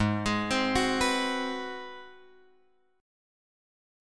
G# 7th Sharp 9th